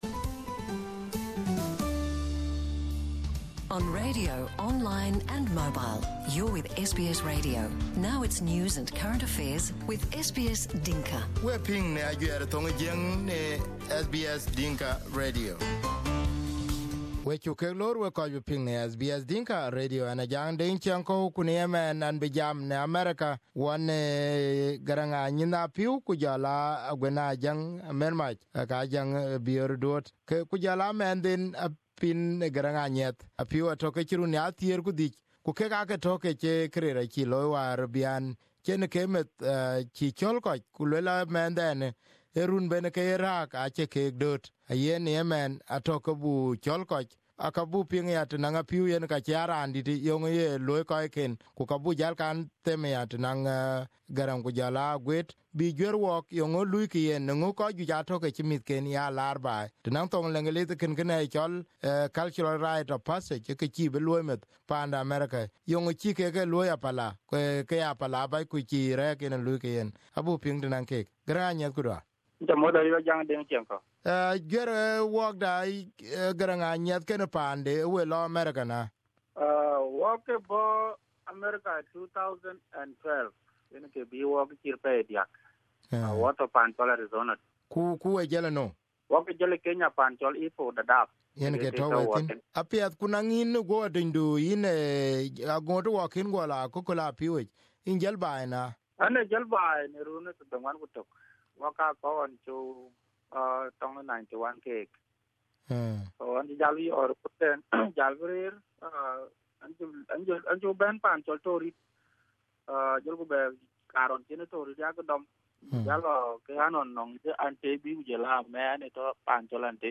Here is the interview on SBS Dinka Radio